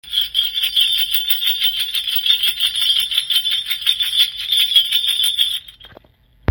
Small Brass Bells
Beautiful brass, clam style embossed jingle bells.